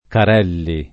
[ kar $ lli ]